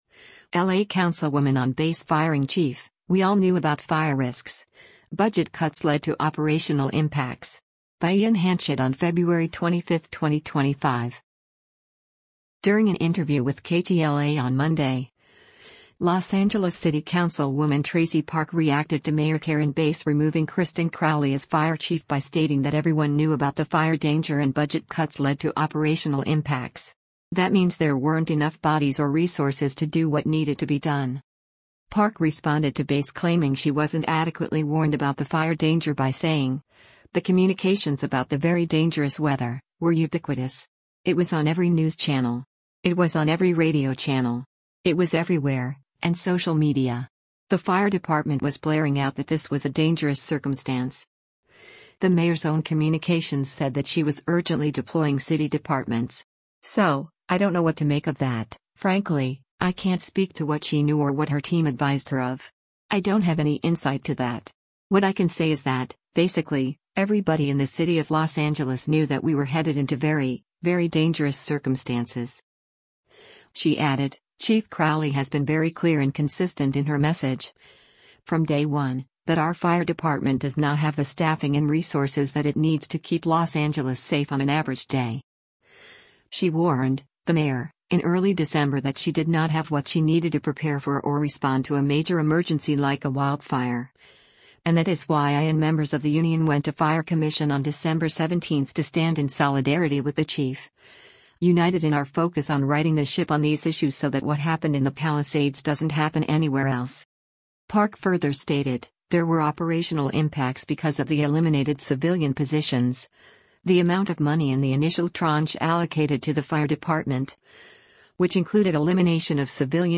During an interview with KTLA on Monday, Los Angeles City Councilwoman Traci Park reacted to Mayor Karen Bass removing Kristin Crowley as Fire Chief by stating that everyone knew about the fire danger and budget cuts “led to operational impacts. That means there weren’t enough bodies or resources to do what needed to be done.”